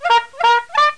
04_HONK.mp3